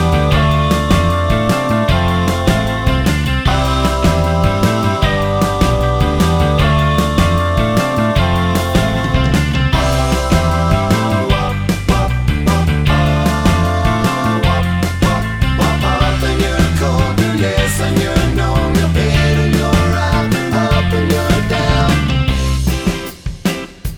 no Backing Vocals Rock 'n' Roll 3:36 Buy £1.50